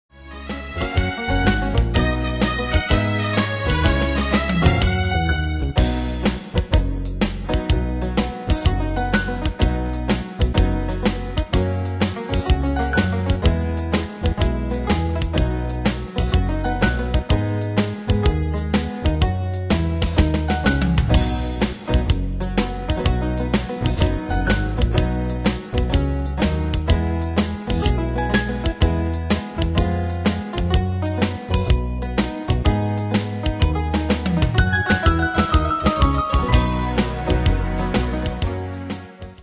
Pro Backing Tracks